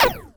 sci-fi_weapon_laser_small_05.wav